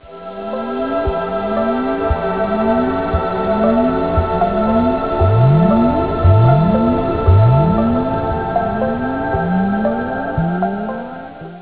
fame and this is his first ambient project.